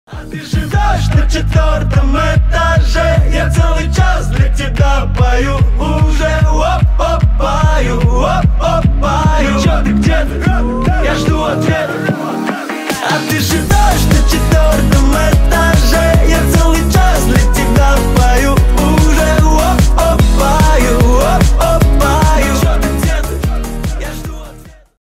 бесплатный рингтон в виде самого яркого фрагмента из песни
Поп Музыка
весёлые